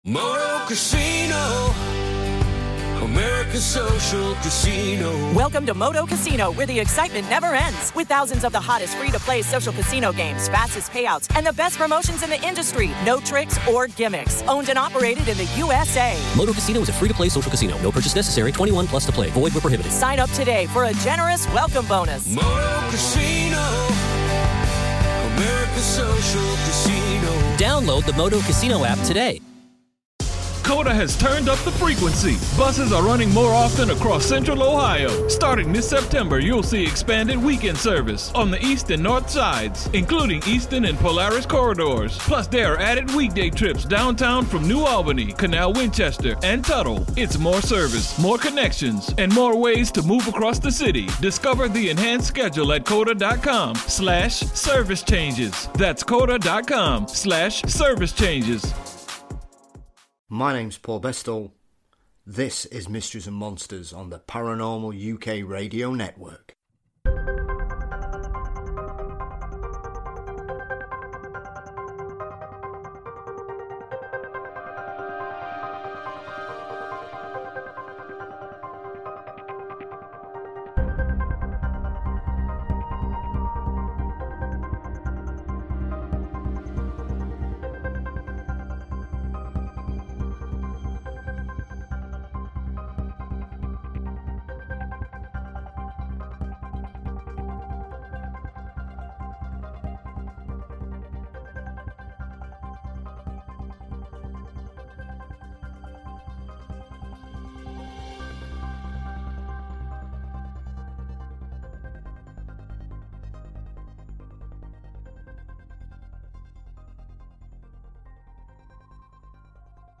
We discuss his love of fortean themes, trends in the fortean world, Bigfoot, the works of Underwood and Wheatley, Ufology, Rendlesham, Ghosts and much more. There's a lot packed in to our 80 minute conversation, so I hope you find it stimulating.